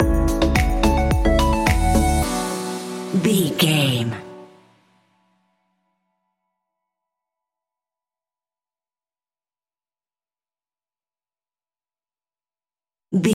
Aeolian/Minor
uplifting
energetic
bouncy
synthesiser
drum machine
house
electro
synth bass